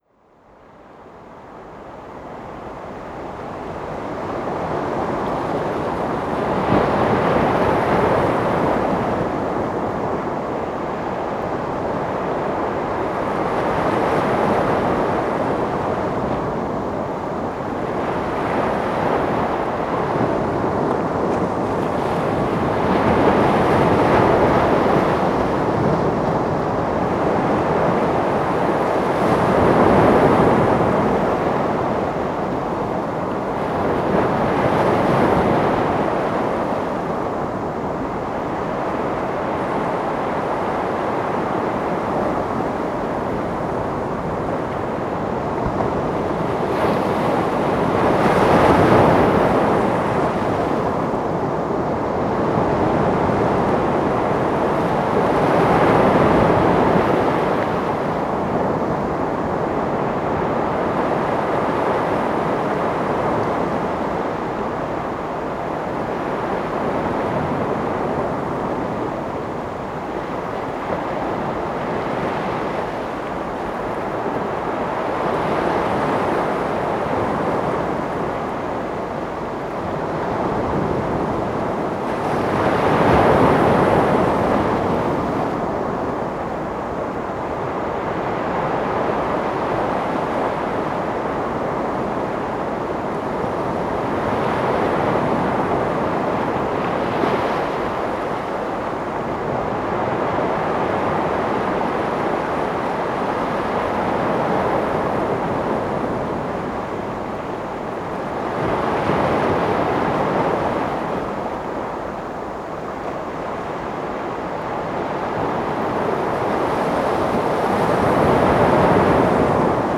Recordings from Juan de Fuca Provincial Park
59. Botanical Beach waves (solo)
59_Botanical_Beach_Waves.wav